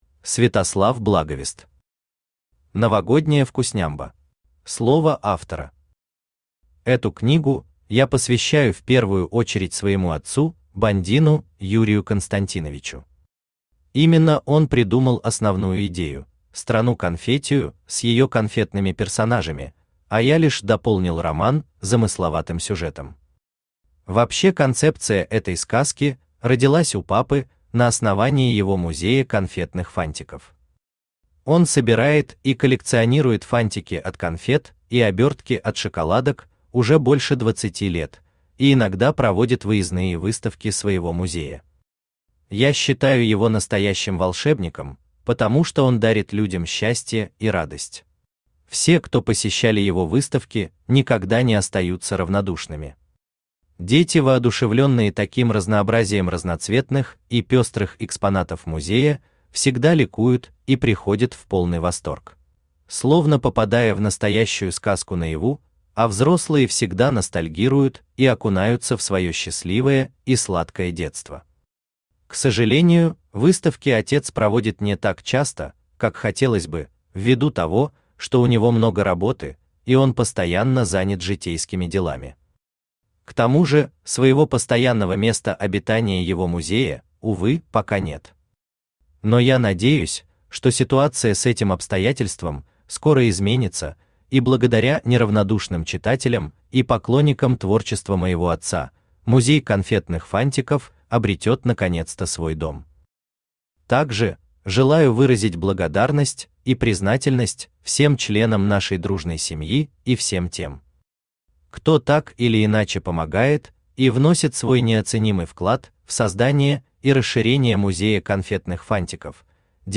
Аудиокнига Новогодняя Вкуснямба | Библиотека аудиокниг
Aудиокнига Новогодняя Вкуснямба Автор Святослав Сергеевич Благовест Читает аудиокнигу Авточтец ЛитРес.